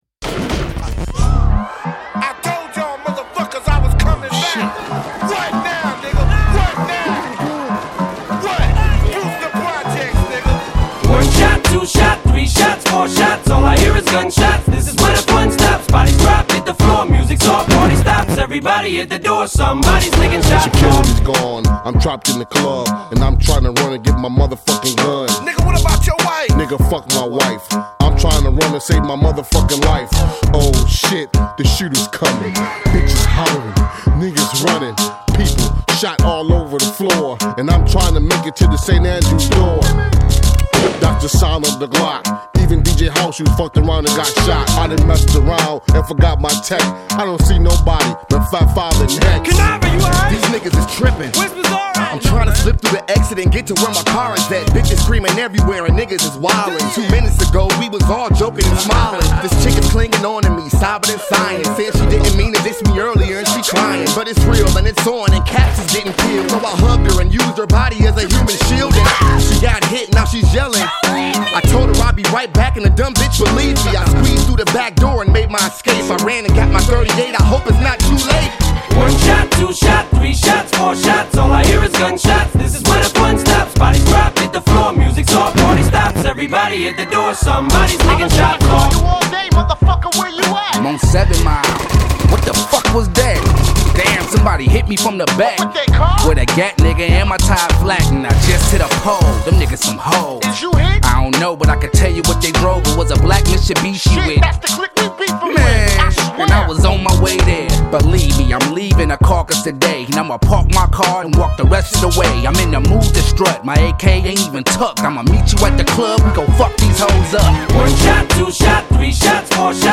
Жанр: Хип-хоп